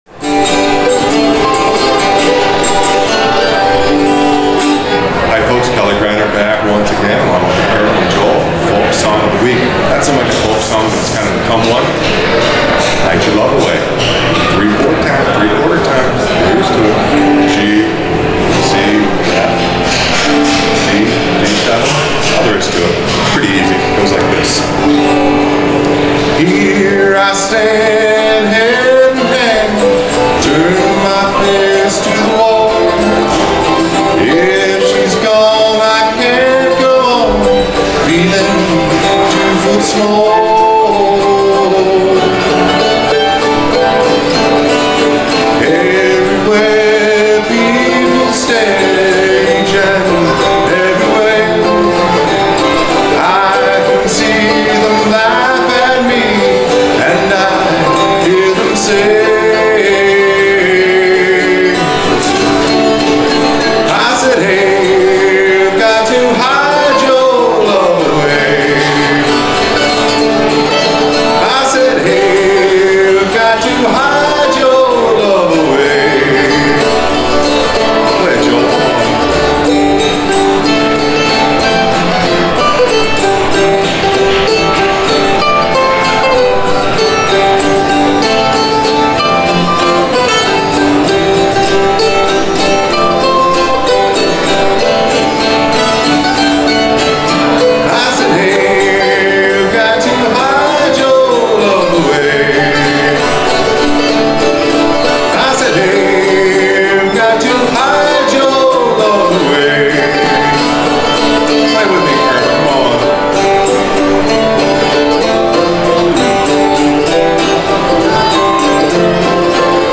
Folk Song Of The Week – Hide Your Love Away – Accompaniment for Frailing Banjo
Live on Tape from iBAM!